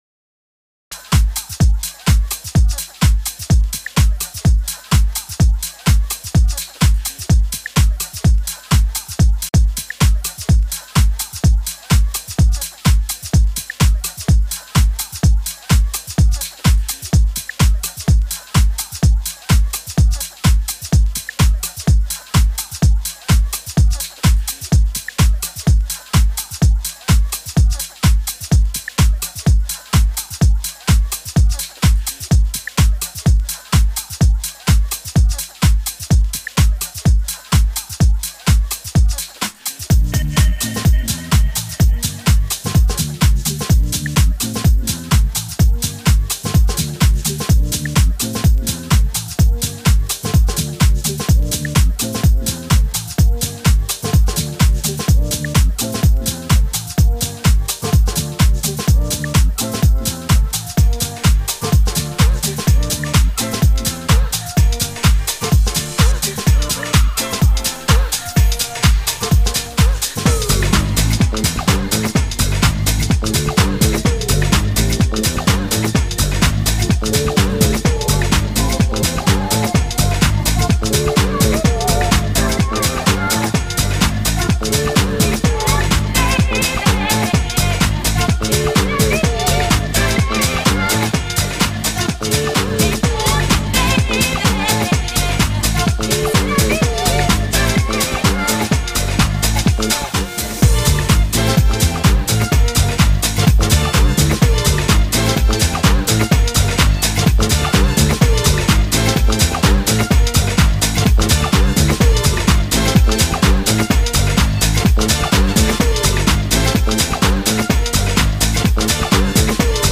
electronic music
from Old School House to Drum & Bass, Techno and more.